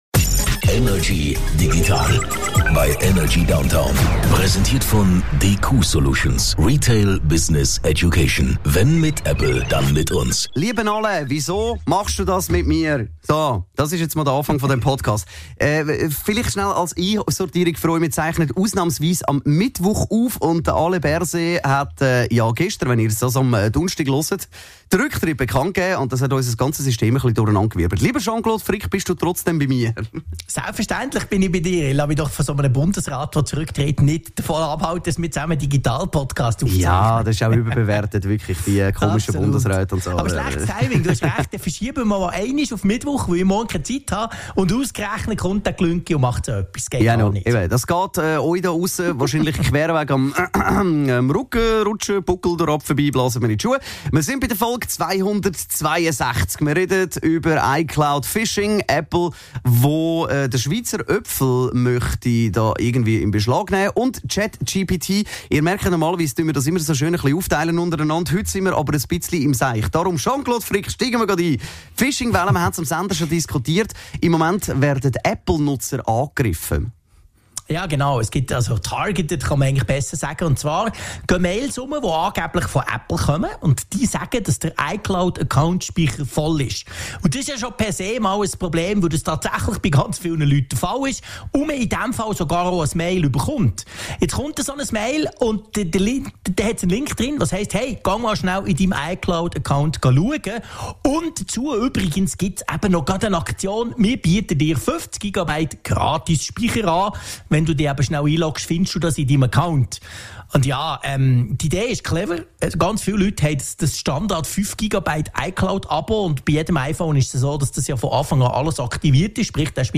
aus dem HomeOffice über die digitalen Themen der Woche.